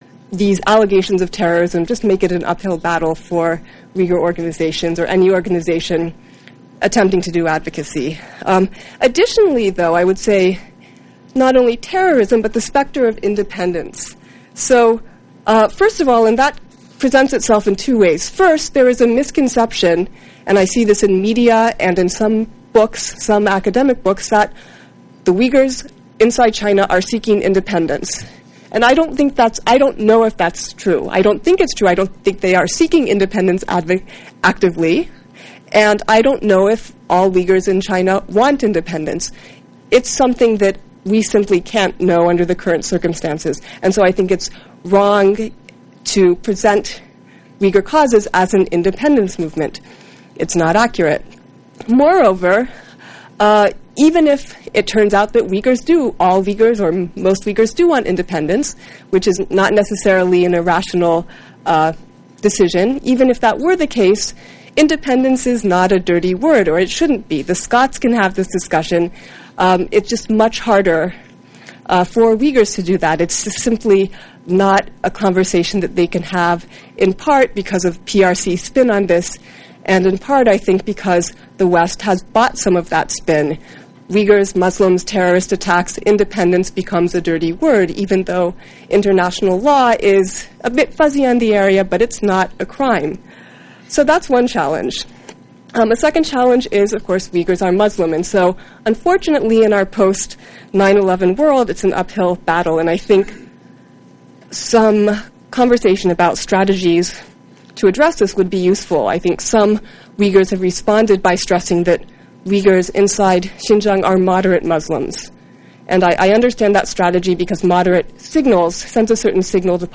Uyg’ur masalasiga bag’ishlangan va g’arbu sharqdan o’nlab olimlarni bir davraga yig’gan birinchi xalqaro konferensiya.